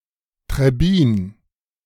Trebbin (German pronunciation: [tʁɛˈbiːn]